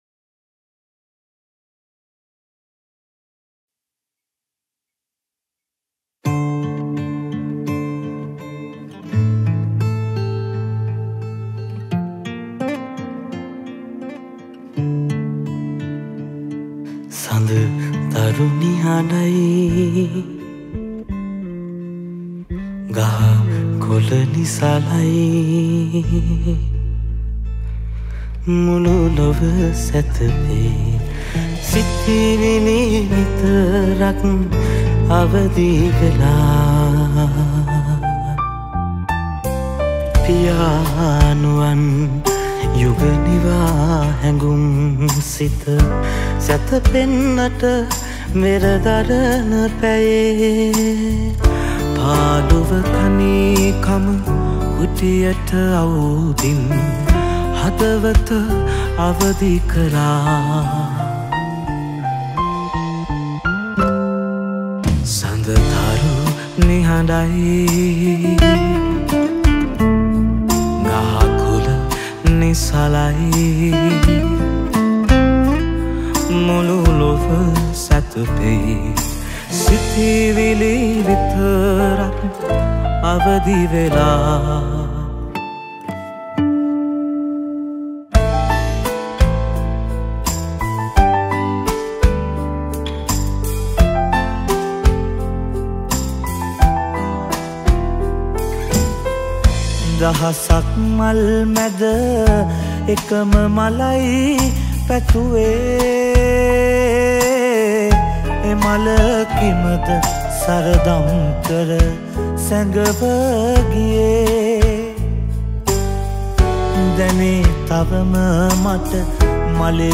Releted Files Of Sinhala Band Medley Songs